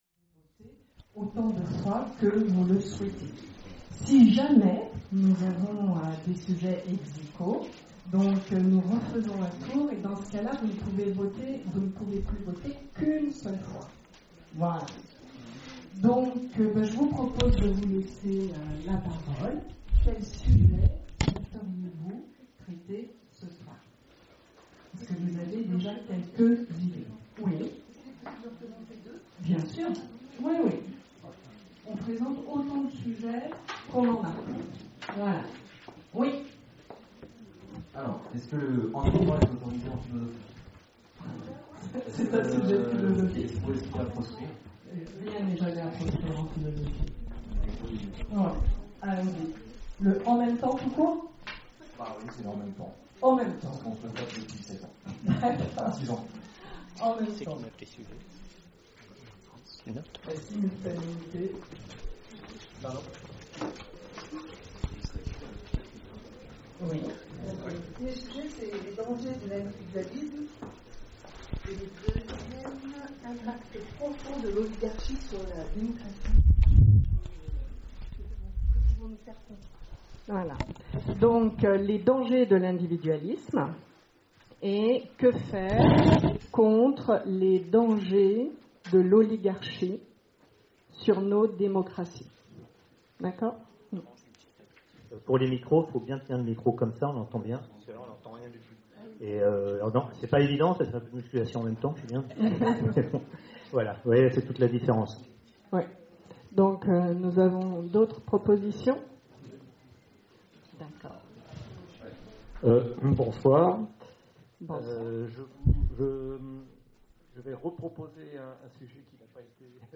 Conférences et cafés-philo, Orléans
CAFÉ-PHILO PHILOMANIA Y-a-t-il un lien entre souffrance et créativité ?